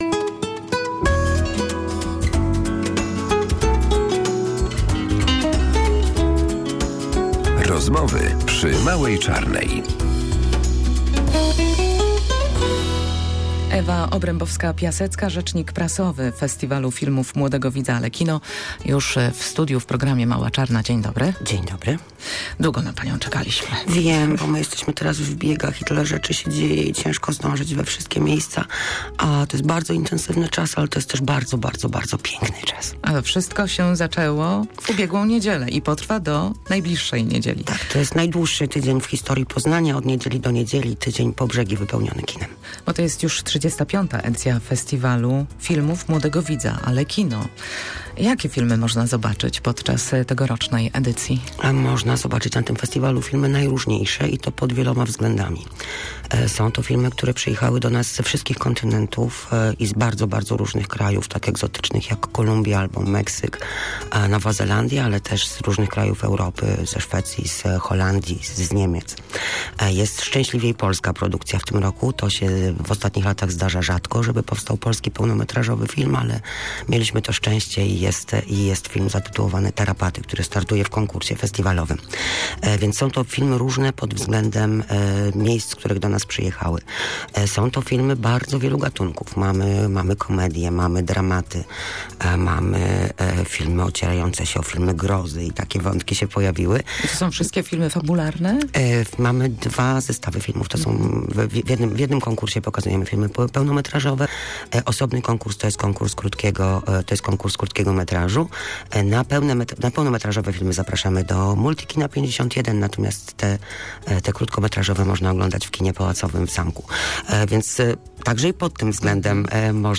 rozmawiała z gościem